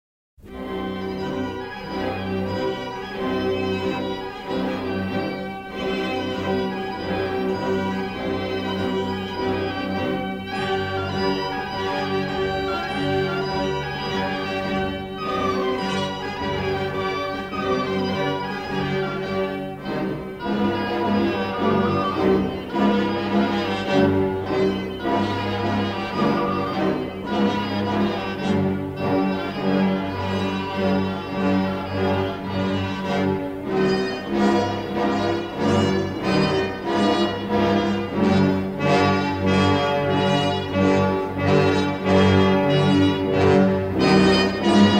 remastered from the original 1/4" stereo album tapes